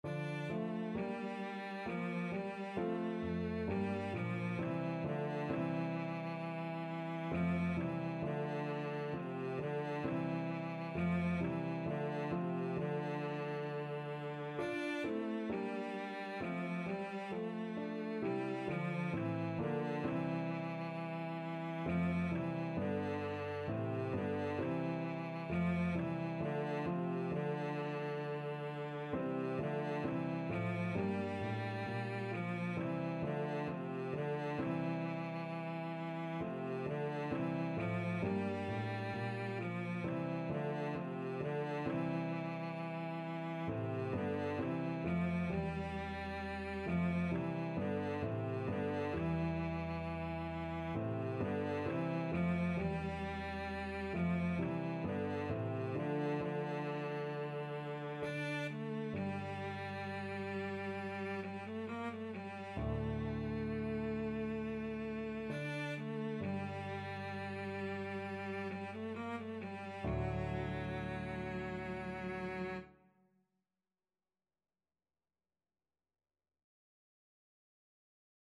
Cello
D minor (Sounding Pitch) (View more D minor Music for Cello )
2/2 (View more 2/2 Music)
Moderato =66
Classical (View more Classical Cello Music)